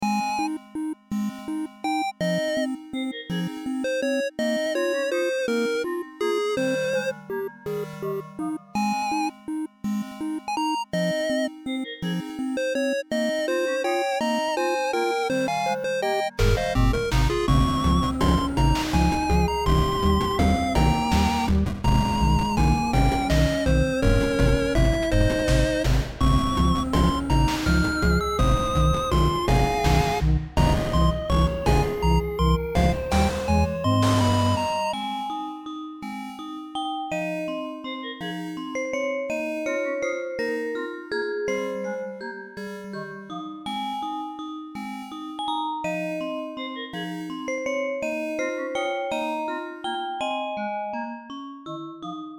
short chiptune 1 i made just now : )